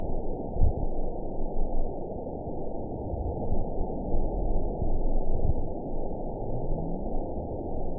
event 919929 date 01/28/24 time 21:53:17 GMT (1 year, 3 months ago) score 9.61 location TSS-AB03 detected by nrw target species NRW annotations +NRW Spectrogram: Frequency (kHz) vs. Time (s) audio not available .wav